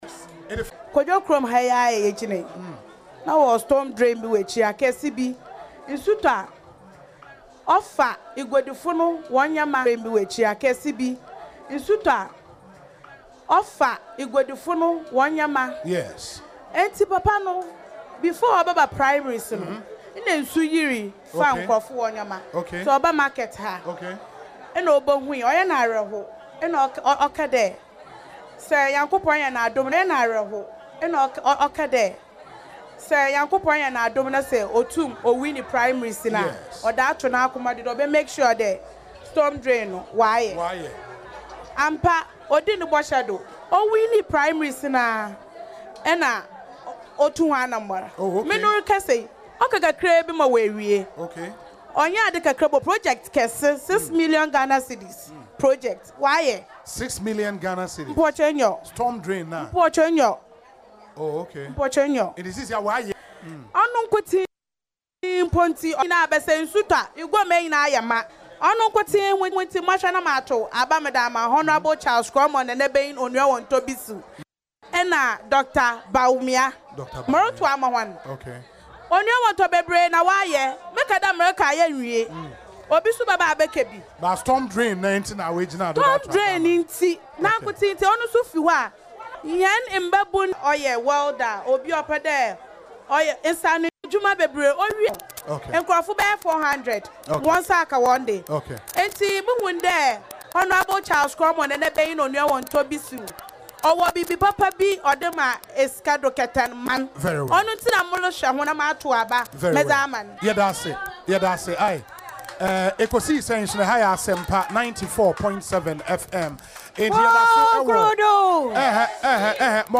Speaking on Asempa FM’s Ekosii Sen community engagement in Sekondi-Takoradi, the market women shared their reasons for supporting Mr. Bissue.